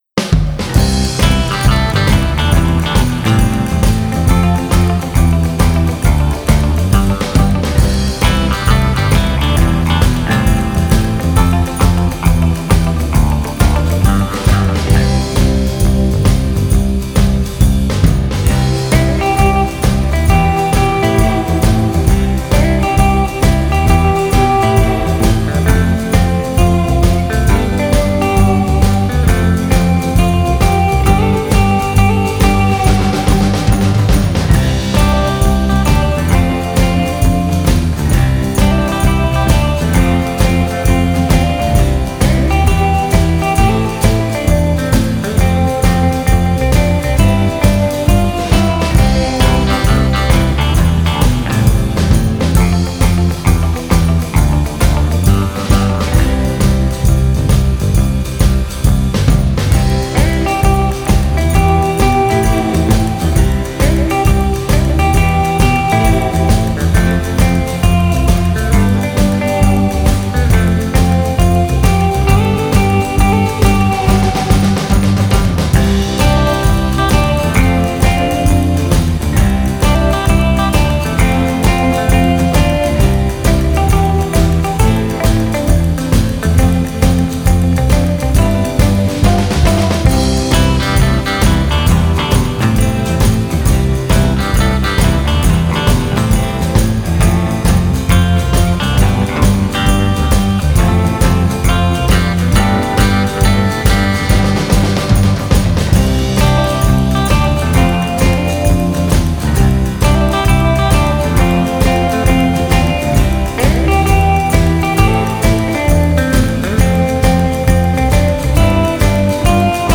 instrumental group